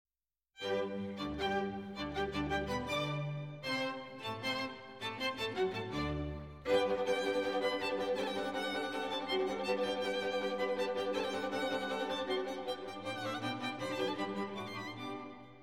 Кря-кря, кря-кря, кря-кряк!
Если бы у людей были достаточно развитые челюстные мышцы, они бы поняли, что пищащие игрушки это превосходный аккомпанемент для Моцарта в ду́ше.